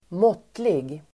Uttal: [²m'åt:lig]